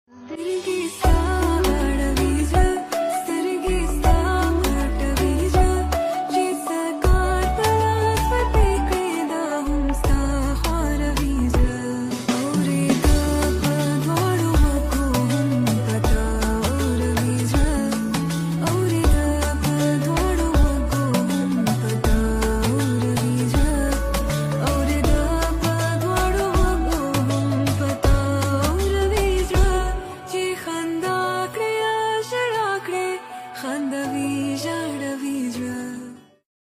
Pushto aesthetic song